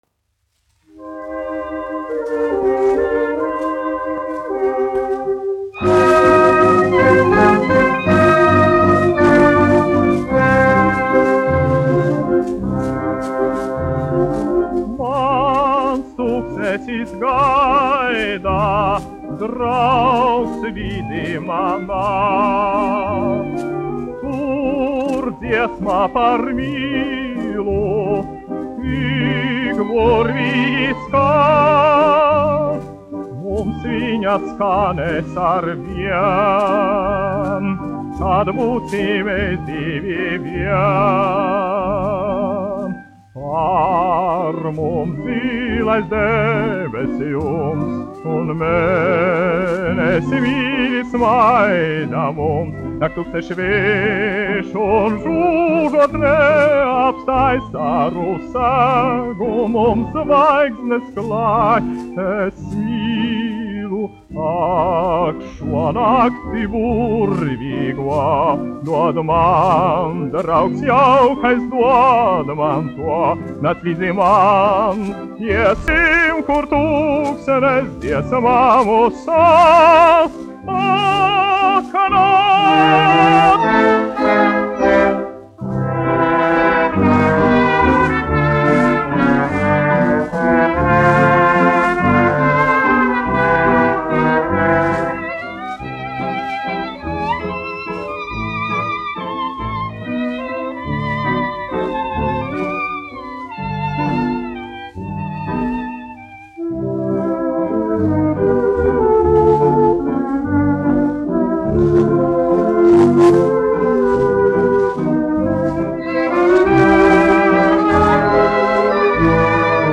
1 skpl. : analogs, 78 apgr/min, mono ; 25 cm
Mūzikli--Fragmenti